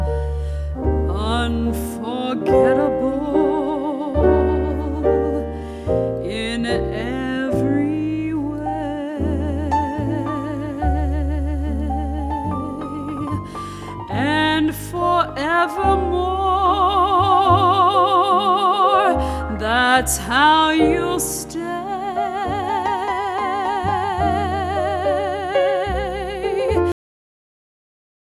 Sample These Song Snippets from the Album